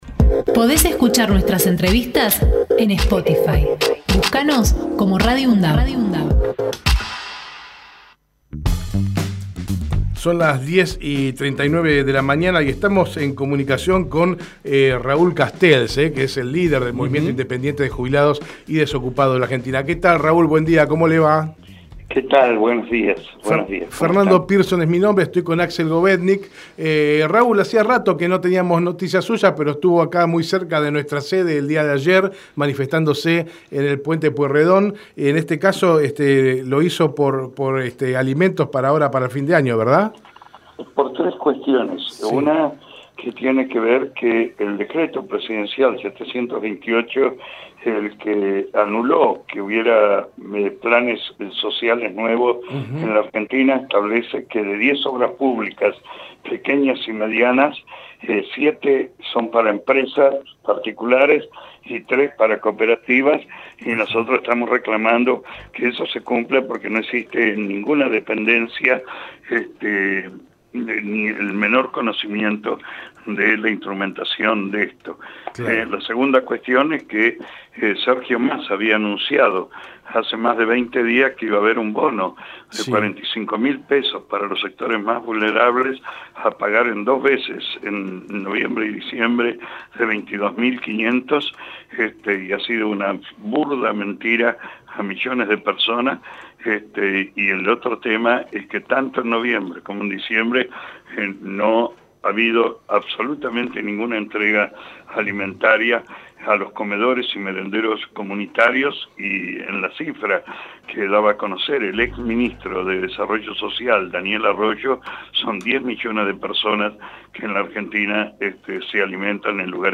Raúl Castells en Hacemos PyE Texto de la nota: Compartimos la entrevista realizada en Hacemos PyE a Raúl Castells, dirigente del Movimiento Independiente de Jubilados y Desocupados (MIJD). Conversamos sobre el reparto de bolsones de comida para fin de año Hacemos PyE de lunes a viernes de 10 a 12 hs. con agenda propia.